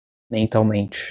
Pronunciado como (IPA) /mẽˌtawˈmẽ.t͡ʃi/